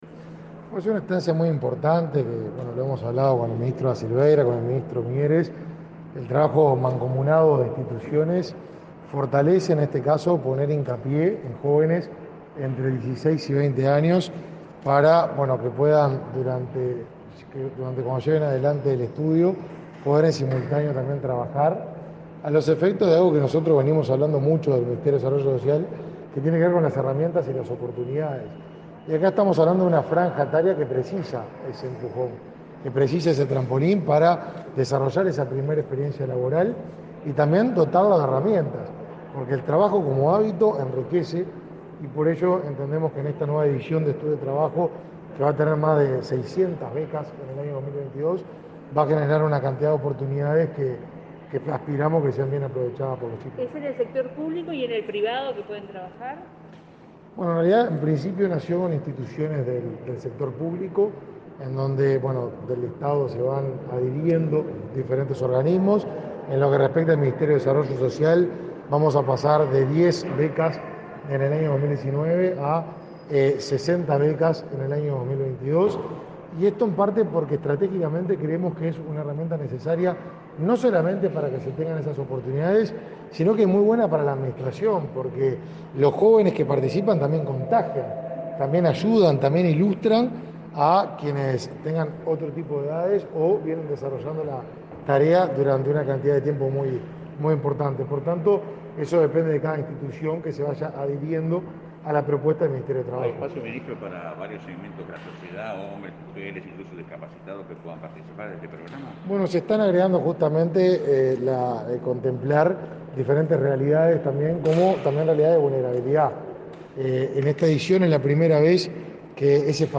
Declaraciones a la prensa de los ministros de Desarrollo Social, y Educación y Cultura
Declaraciones a la prensa de los ministros de Desarrollo Social, y Educación y Cultura 23/11/2021 Compartir Facebook X Copiar enlace WhatsApp LinkedIn El ministro de Desarrollo Social, Martín Lema, y su par de Educación y Cultura, Pablo da Silveira, participaron este martes 23 en el lanzamiento de una nueva edición del programa Yo Estudio y Trabajo y, luego, dialogaron con la prensa.